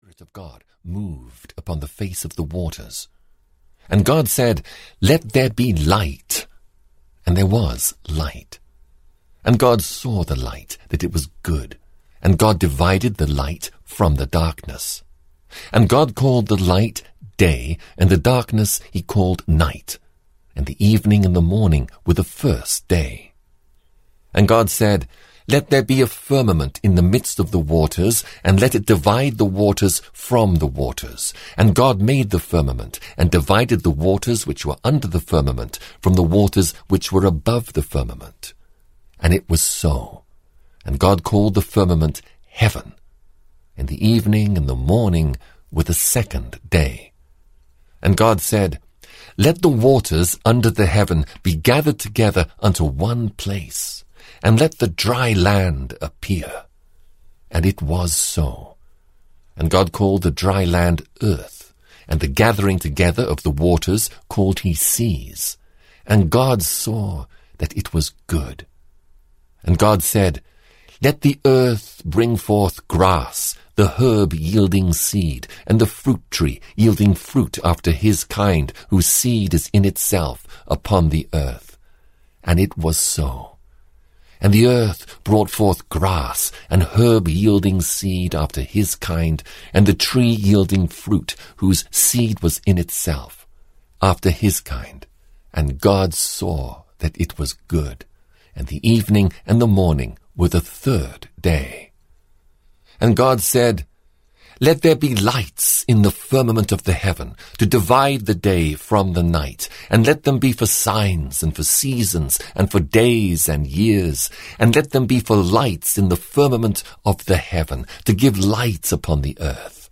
The Old Testament 1 - Genesis (EN) audiokniha
Ukázka z knihy